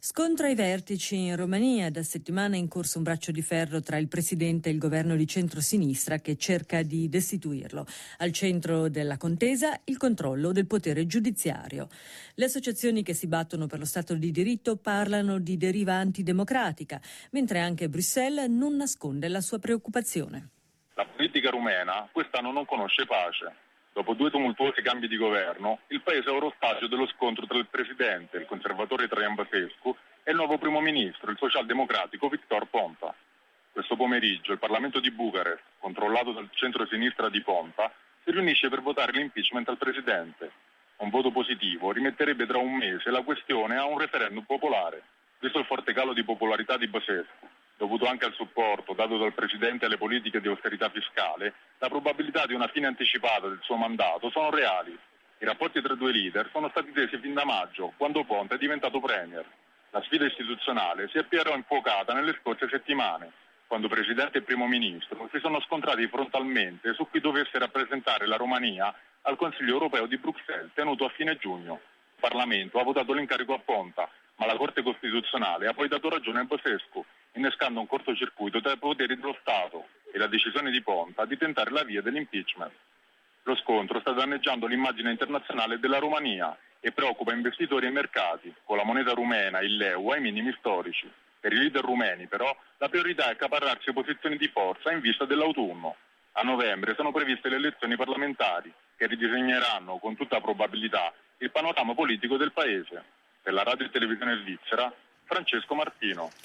Dopo due cambi di governo, oggi il paese è ostaggio dello scontro tra il presidente Traian Băsescu e il premier Victor Ponta. Oggi pomeriggio il parlamento di Bucarest potrebbe sospendere Băsescu, il cui destino sarebbe poi legato ad un referendum da tenere tra un mese. Il servizio